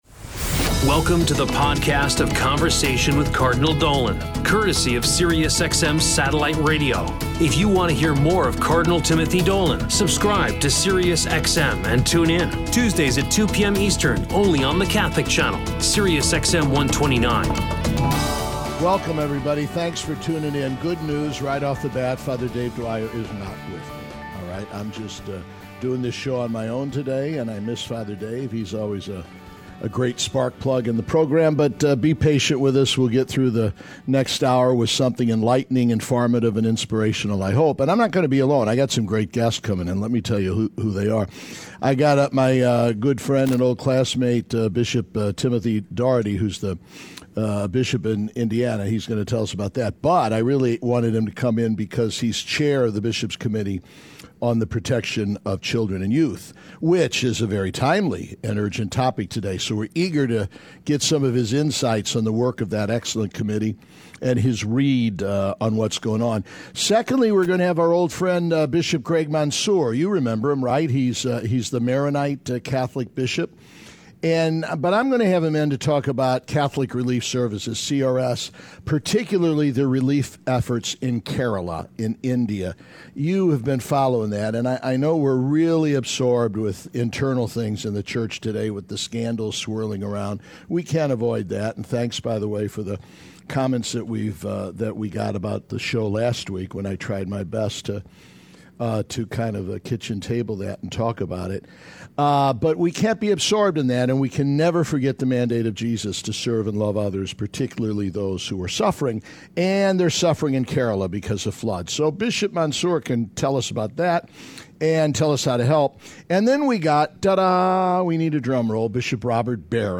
Conversation with Cardinal Dolan: September 11, 2018